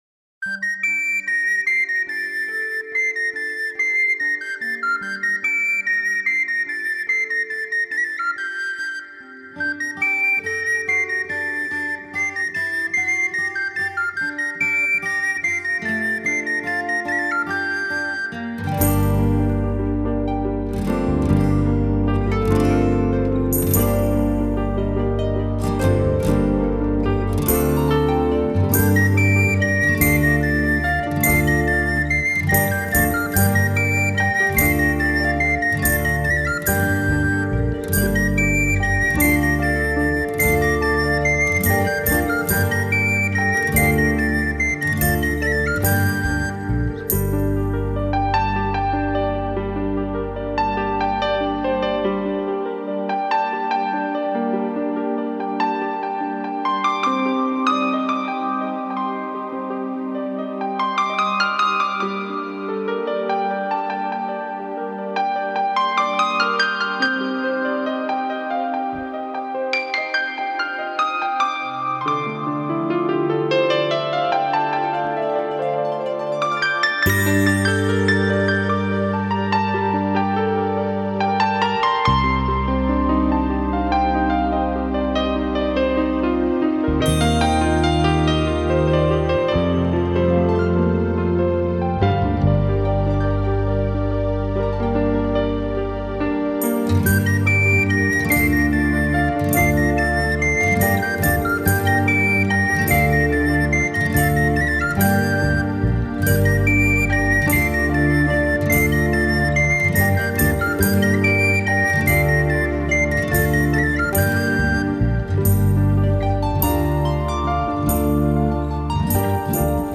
幻化成一篇篇如詩夢般美麗迷人的樂章
在這張專輯中，他身兼鍵盤、鋼琴和Bass演出，從容不迫、游刃有餘的功力令人嘆為觀止。
他以清晰的節奏感來平衡浪漫的曲風，不侷限於軟調的呢喃，你可以感受到一種清新，與徹底自由的喜悅！